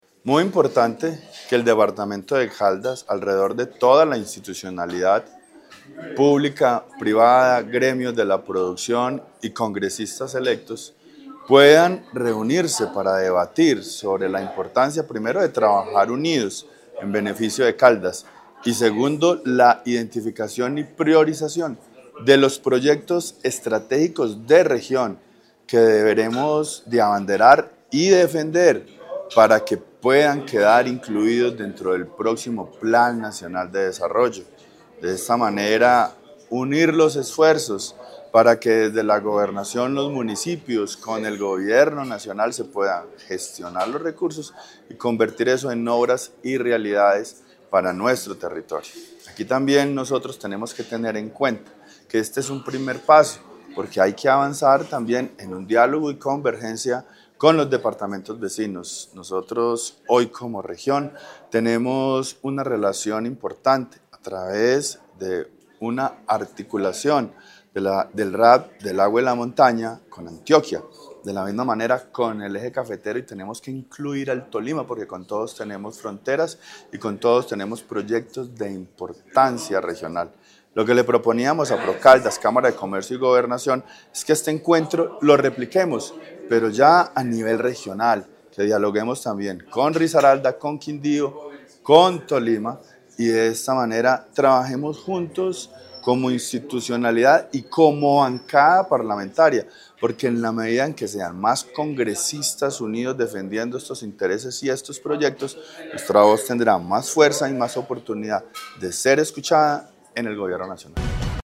Con un mensaje de articulación y trabajo conjunto, la Secretaría de Planeación de la Gobernación de Caldas lideró el encuentro estratégico ‘Caldas Marca la Ruta: una visión de territorio para los próximos años’, un espacio que reunió a la institucionalidad, el sector privado, la academia y la bancada parlamentaria electa para construir una agenda común que proyecte al departamento en el escenario nacional.
Wilder Iberson Escobar, Senador Electo.